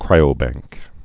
(krīə-băngk)